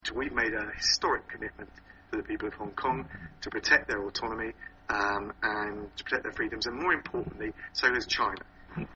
ສຽງທ່ານໂດມີນິກ ແຣບ